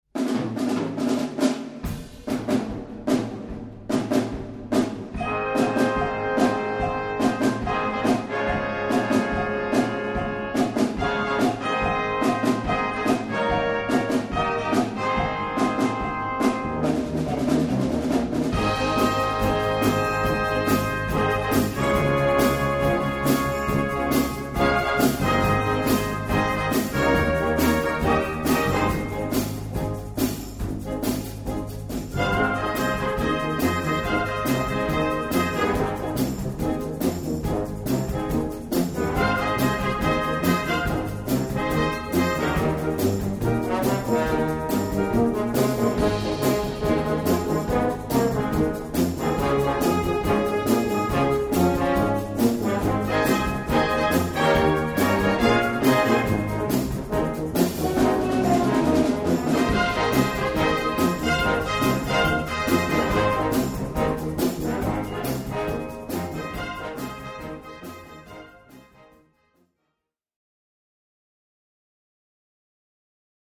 3:50 Minuten Besetzung: Blasorchester PDF